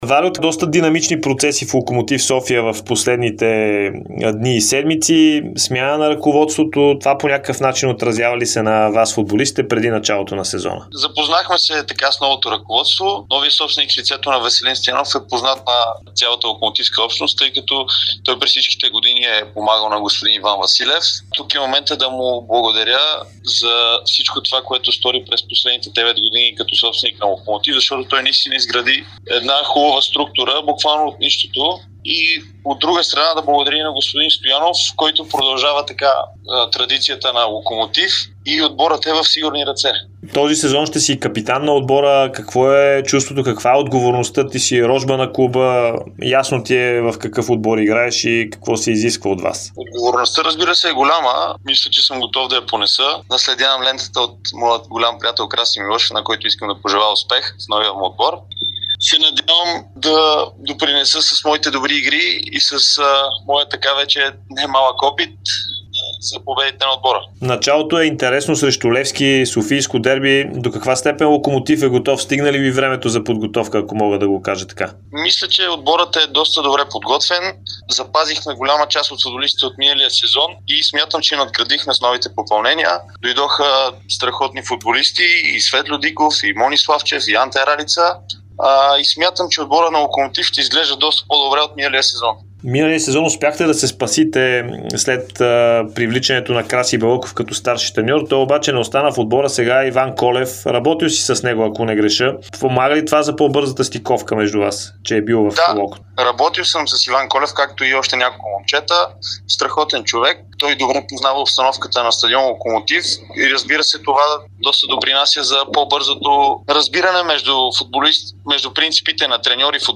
специално интервю